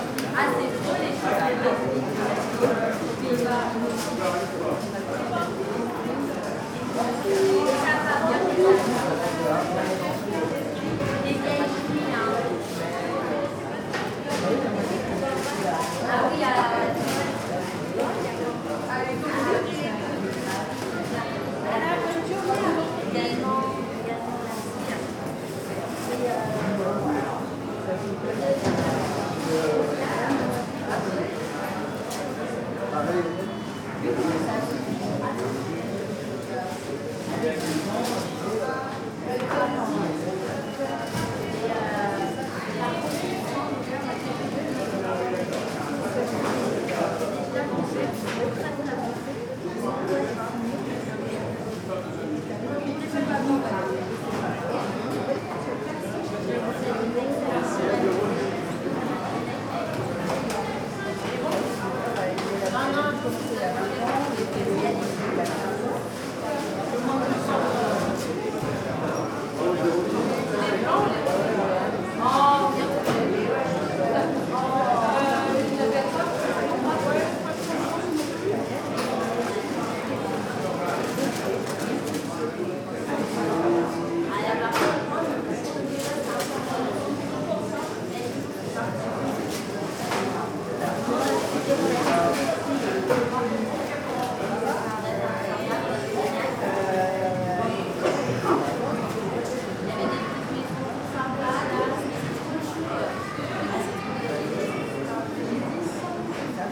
Covered market #1 — Free Sound Effect Download | BigSoundBank
Henri-Barbusse covered market hall in Levallois-Perret. Few people.